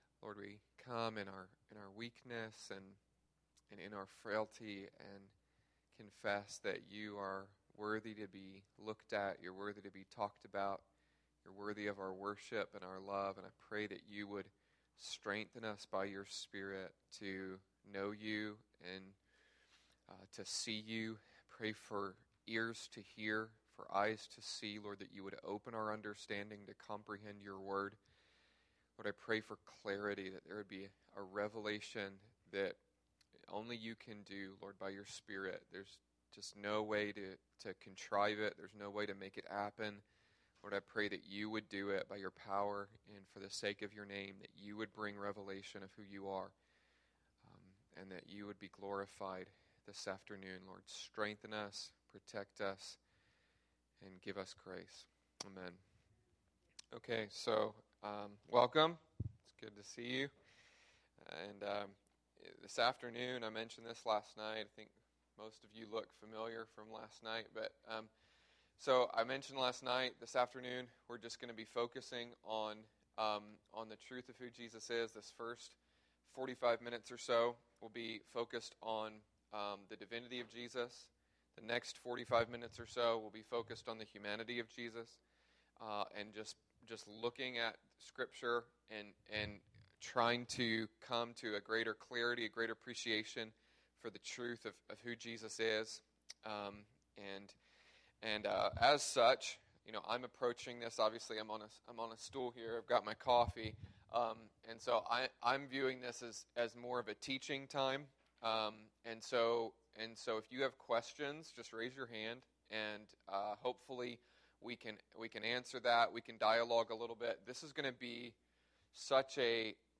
Spoken on September 15th, 2012 at the International House of Prayer East Bay . This is the second session of the Passion for Jesus series from the event held on this weekend.